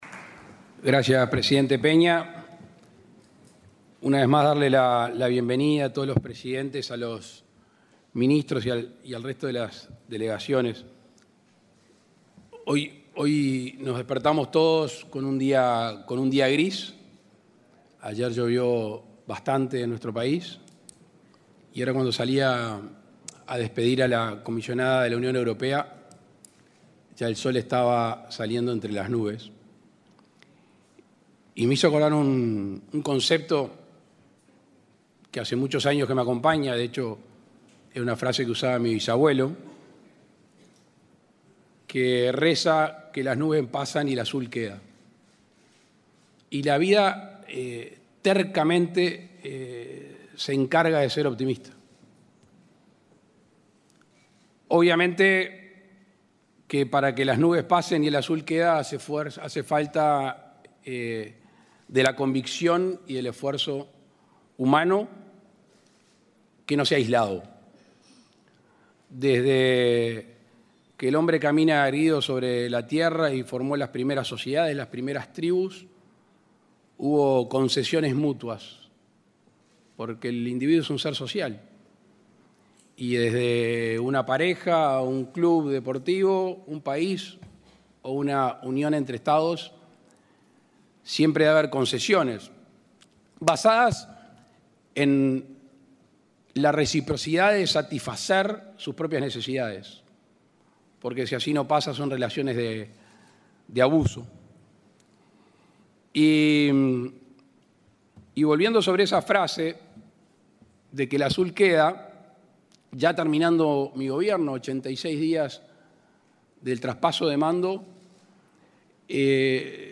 Palabras del presidente Luis Lacalle Pou
Palabras del presidente Luis Lacalle Pou 06/12/2024 Compartir Facebook X Copiar enlace WhatsApp LinkedIn Este viernes 6 en Montevideo, el presidente de la República, Luis Lacalle Pou, intervino en la LXV Cumbre de Presidentes de los Estados Parte del Mercosur y Estados Asociados.